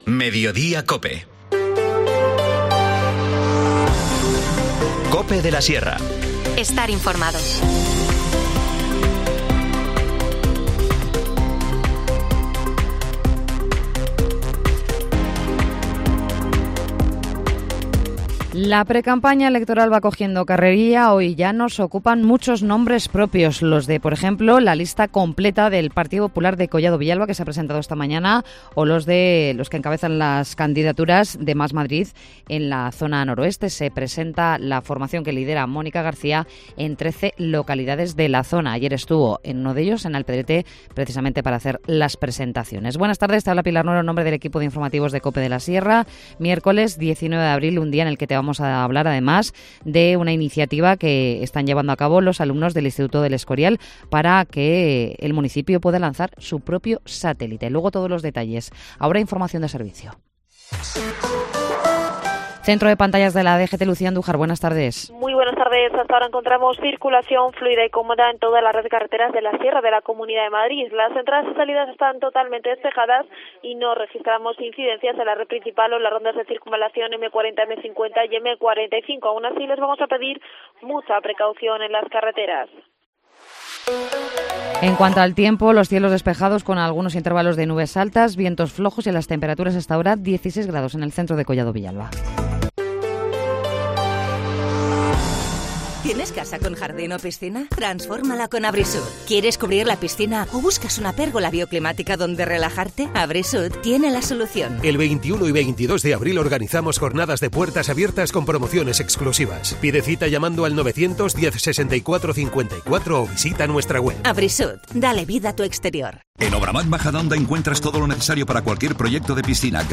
Informativo Mediodía 19 abril